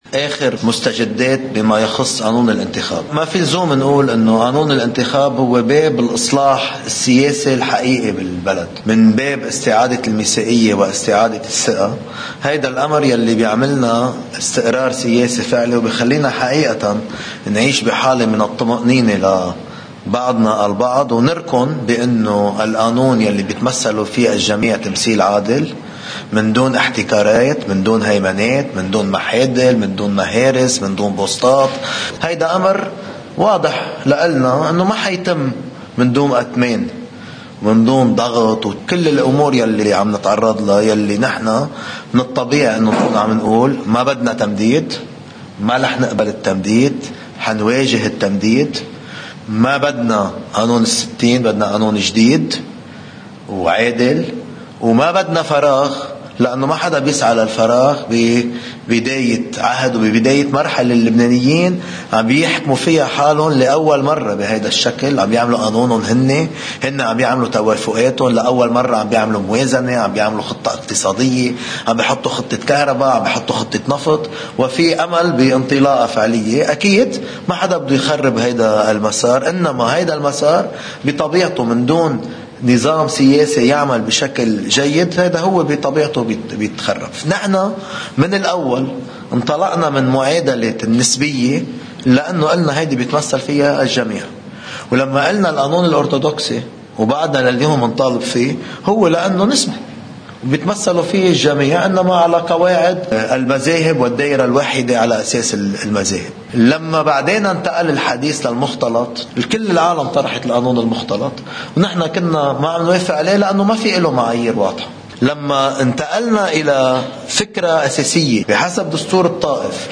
مقتطف من حديث رئيس التيار الوطني الحرّ جبران باسيل إثر الاجتماع الشهري للمجلس السياسي: